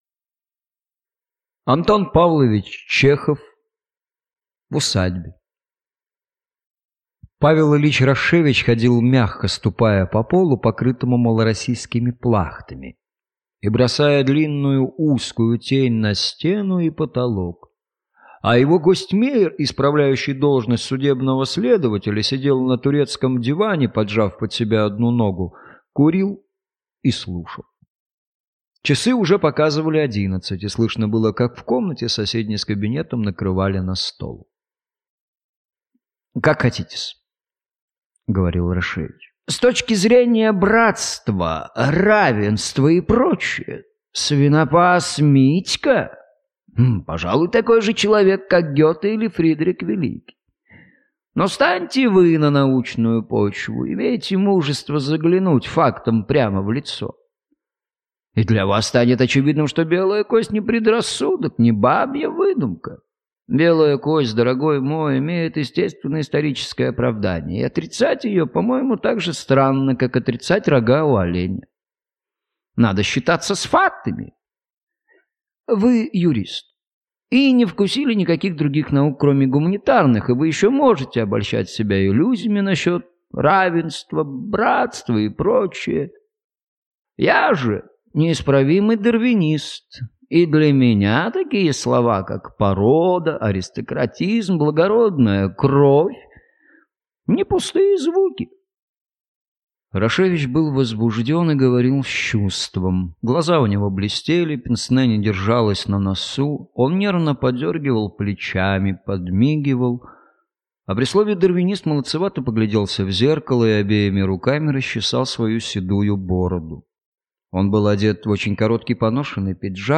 Аудиокнига В усадьбе | Библиотека аудиокниг